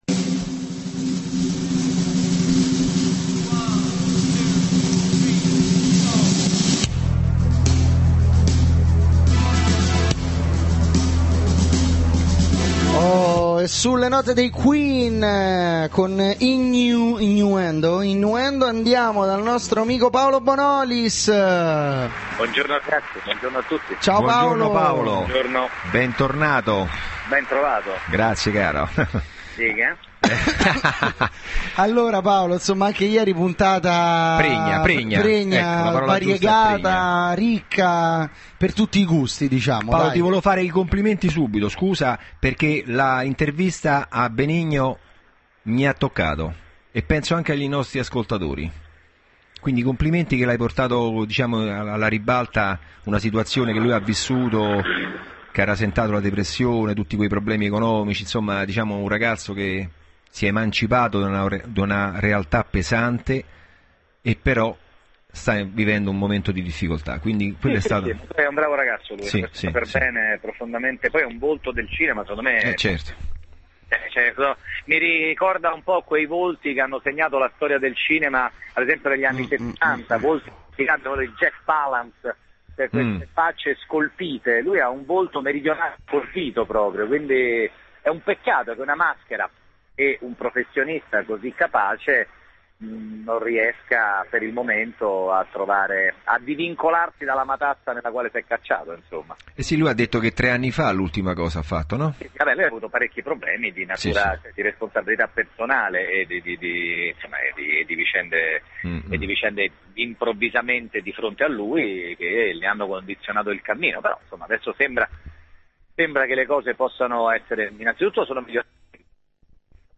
Intervento telefonico Paolo Bonolis del 02/05/2011